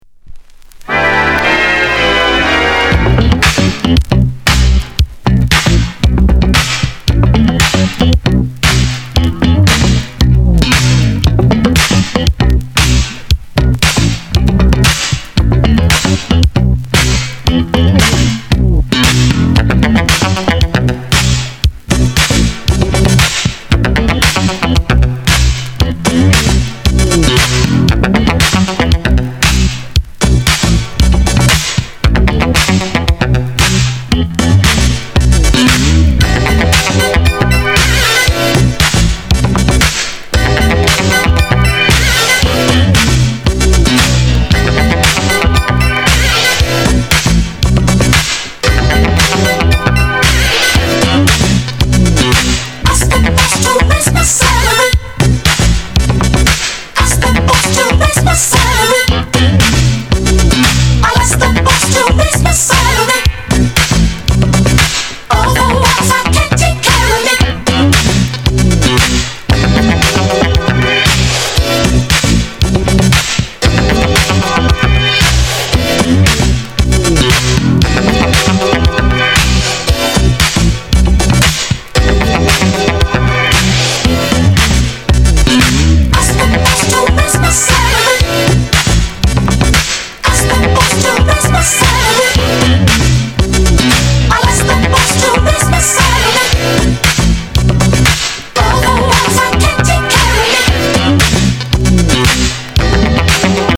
Genre: Soul